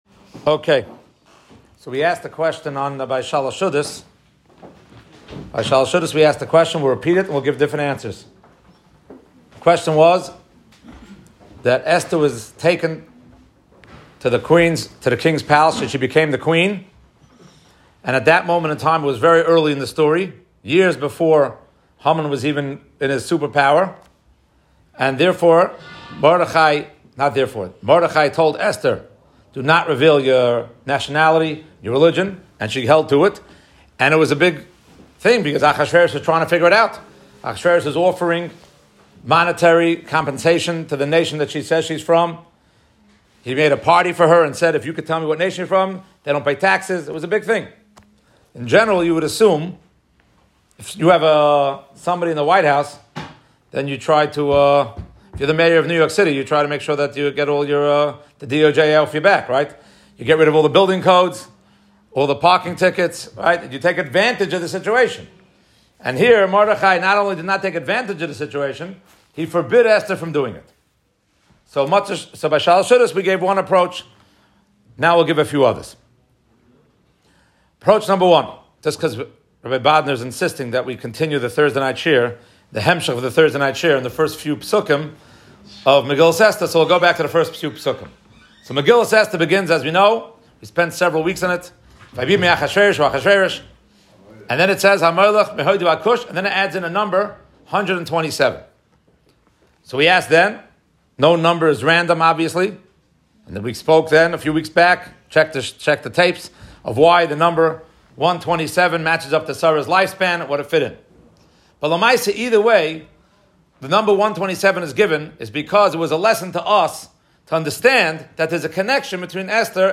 From Young Israel Beth El, Brooklyn: